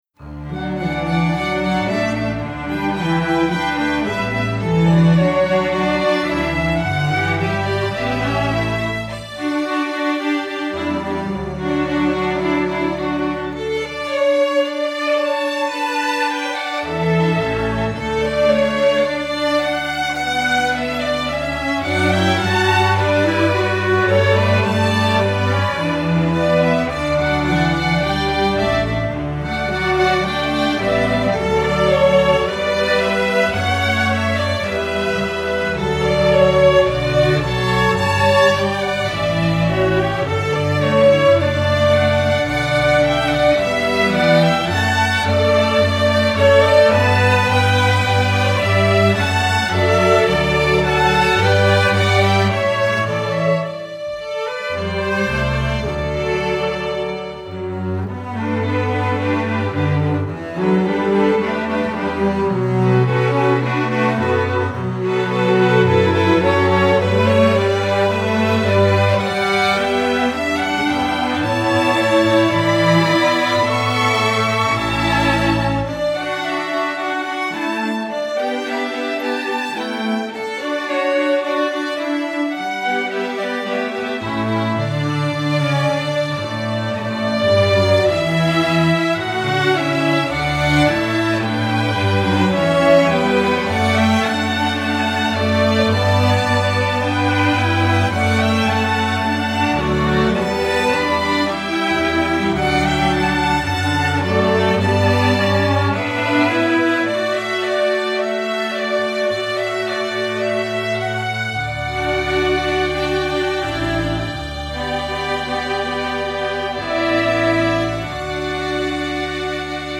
Instrumentation: string orchestra
Piano accompaniment part: